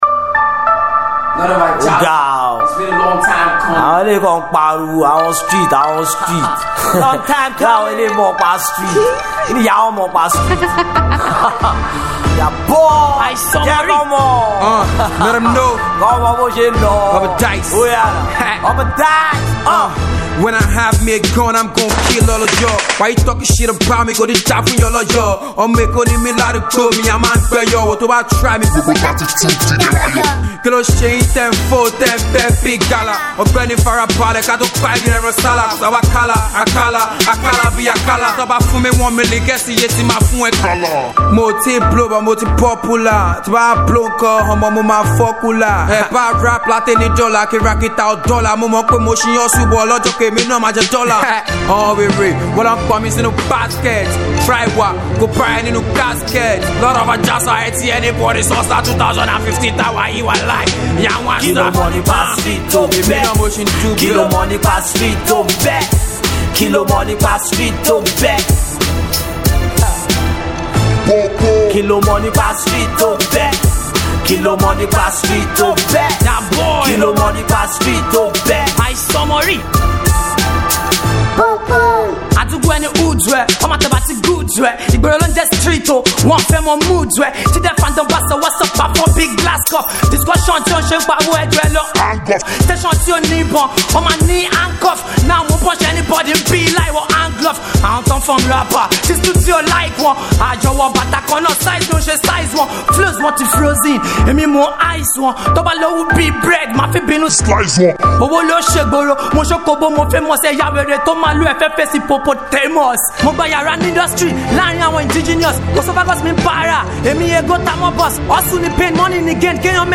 indigenous Hip-Hop, Yoruba Music
featuring rising indigenous rappers